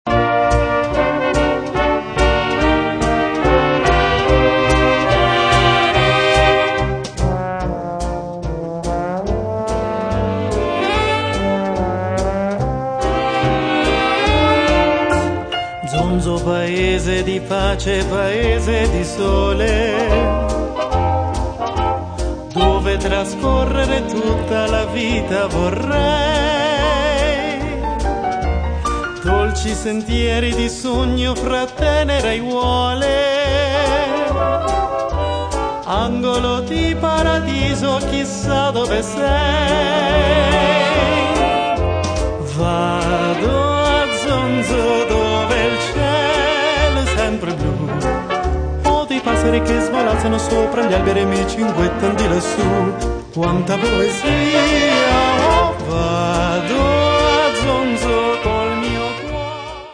pianoforte
sax contralto, clarinetto
sax tenore
tromba
trombone
chitarra
contrabbasso
batteria